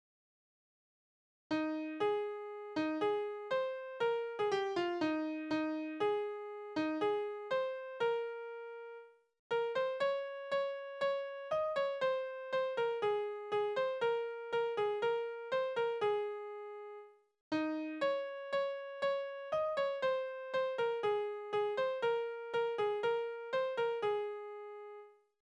Schelmenlieder: Der faule Handwerksbursch
Tonart: As-Dur
Taktart: 4/4
Tonumfang: Oktave
Besetzung: vokal